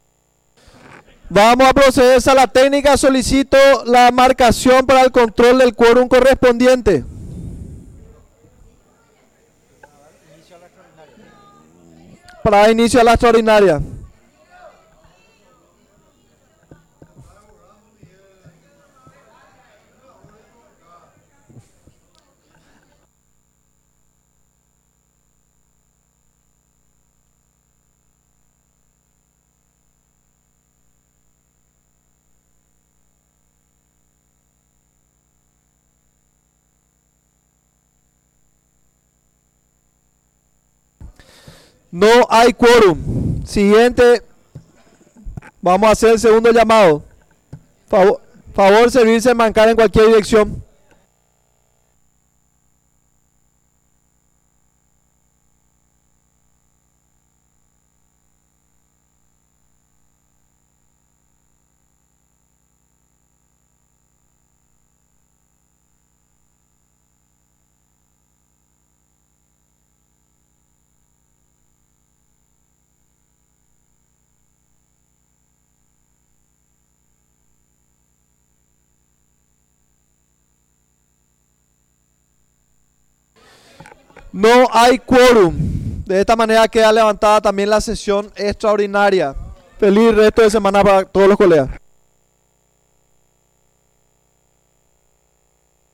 Sesión Extraordinaria, 22 de abril de 2025